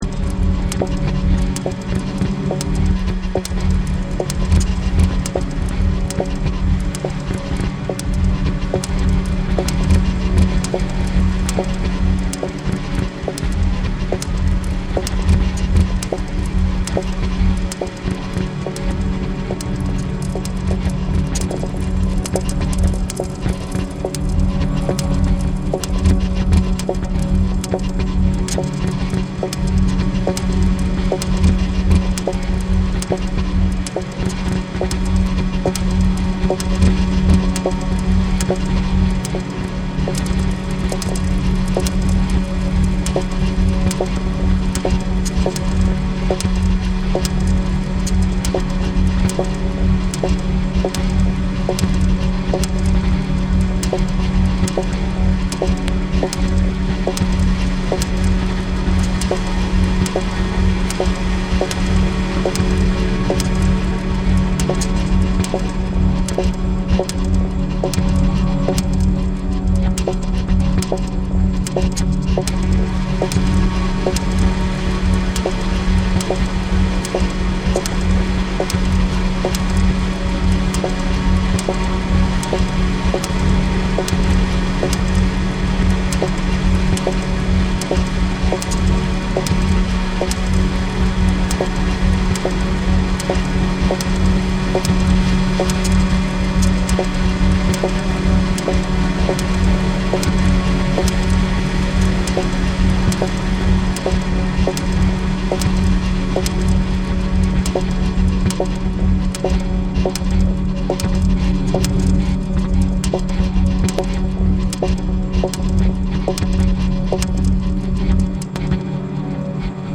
BREAKBEATS
カリビアン・テイストなトロピカルナンバーから、もちろんレゲエ〜ダブまで楽しめる隠れた（？）好作！